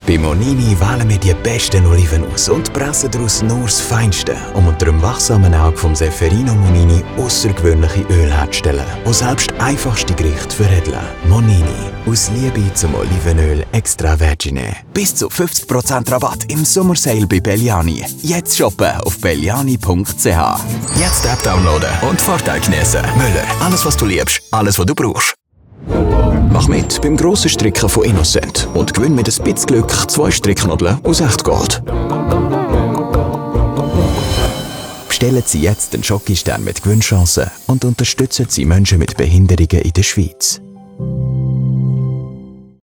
Neutral Swiss German Reel
An authentic, real narrative voice.